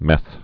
(mĕth)